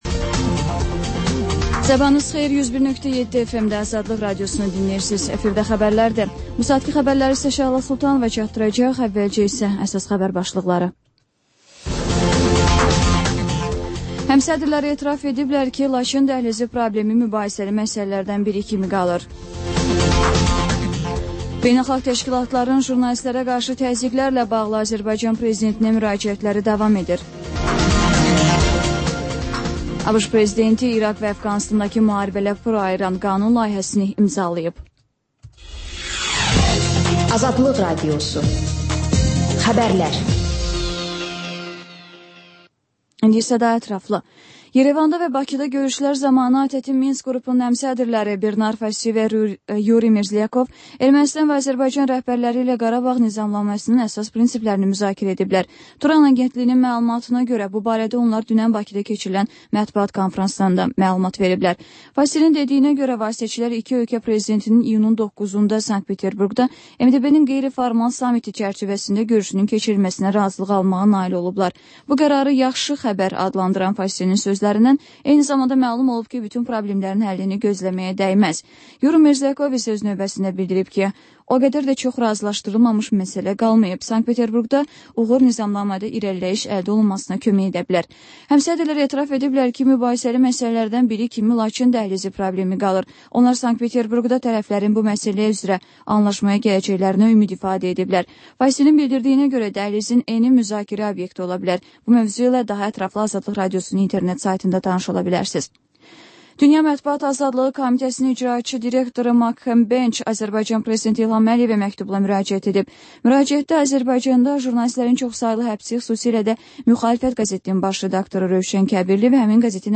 Xəbərlər, ardınca XÜSUSİ REPORTAJ rubrikası: Ölkənin ictimai-siyasi həyatına dair müxbir araşdırmaları. Sonda isə TANINMIŞLAR verilişi: Ölkənin tanınmış simalarıyla söhbət